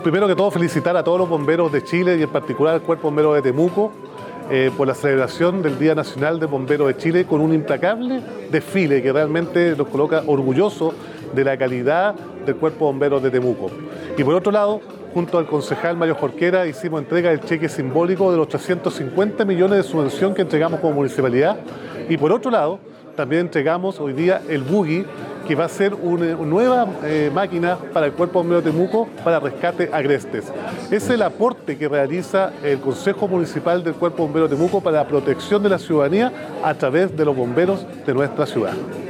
La entrega se realizó en el marco del tradicional desfile por el Día Nacional del Bombero Voluntario, desarrollado en la Plaza Aníbal Pinto, donde se congregaron los distintos cuerpos de la comuna, Padre Las Casas y Freire, incluyendo las localidades de San Ramón, Quepe y Metrenco.
El alcalde de Temuco, Roberto Neira, felicitó a bomberos de Temuco por la celebración de su día nacional, destacando el aporte municipal en beneficio de los voluntarios.
Roberto-Neira-alcalde-Temuco.mp3